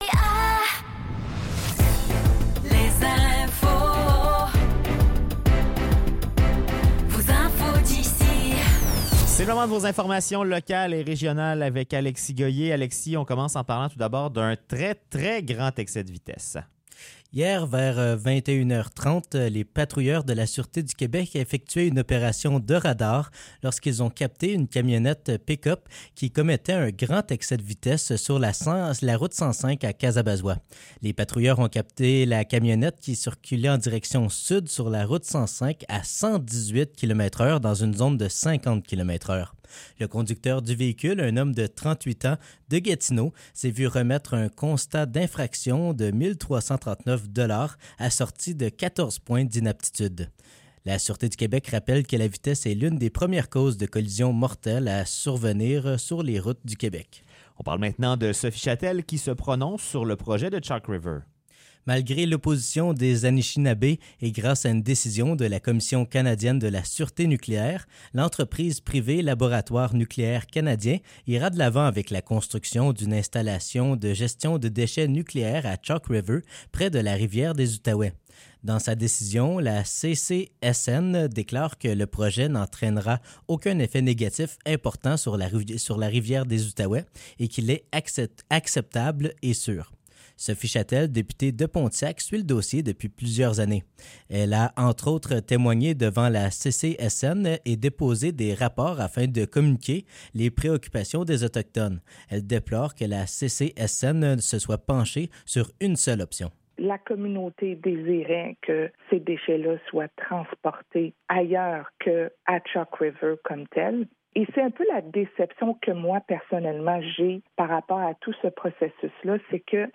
Nouvelles locales - 12 janvier 2024 - 16 h